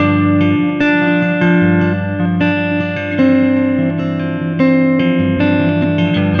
Weathered Guitar 03.wav